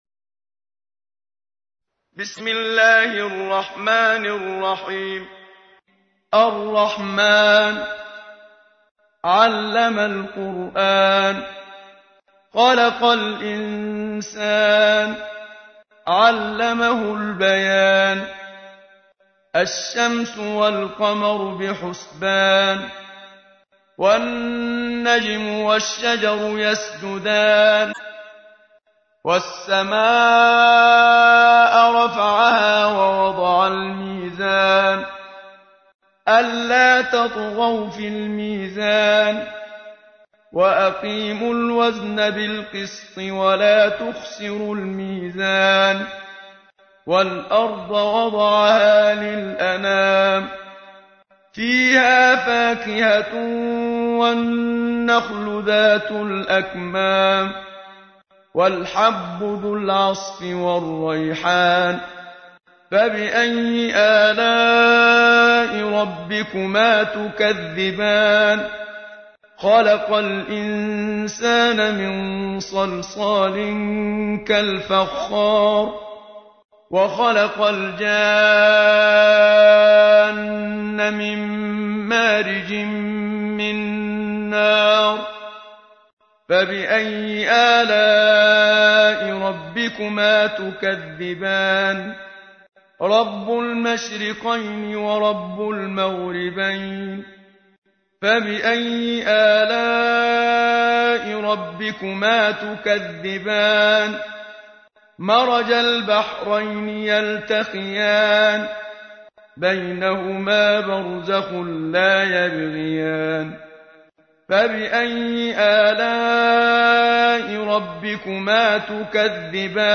تحميل : 55. سورة الرحمن / القارئ محمد صديق المنشاوي / القرآن الكريم / موقع يا حسين